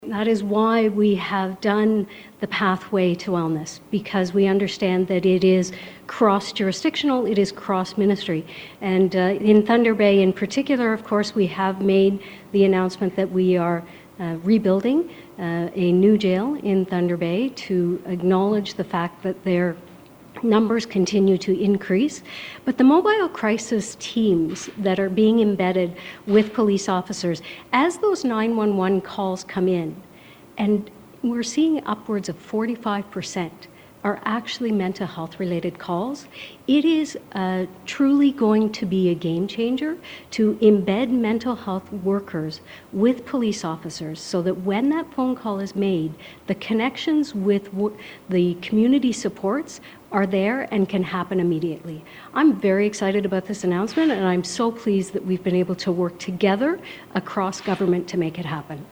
During a media briefing Tuesday, Jones addressed a question about helping northwestern Ontario become addiction free.